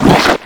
vacushit3.wav